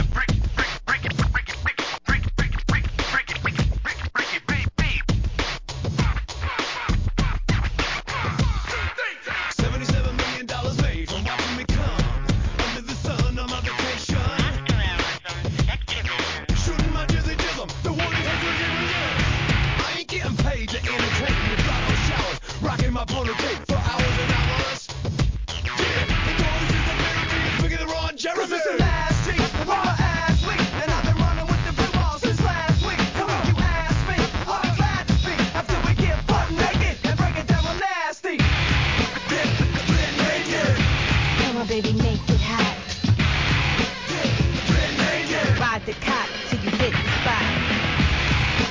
HIP HOP/R&B
ミクスチャー